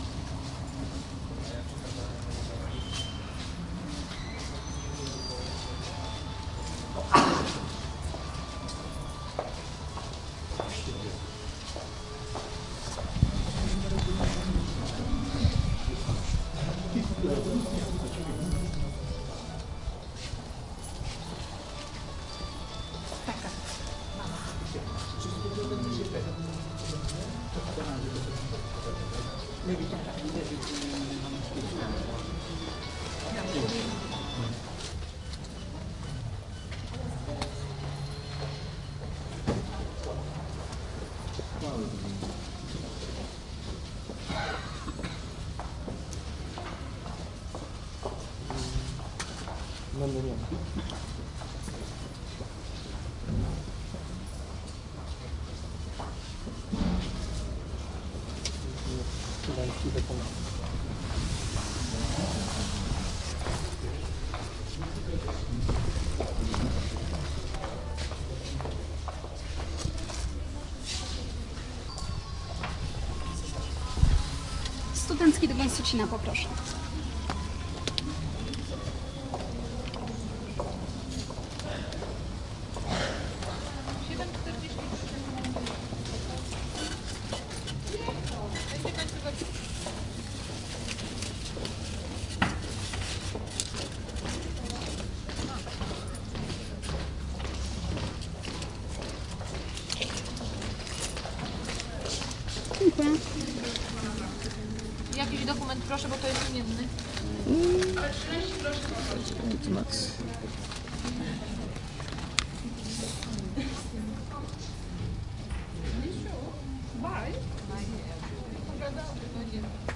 火车到达站
描述：到达车站的火车的声音
Tag: 铁路 车站 列车 到达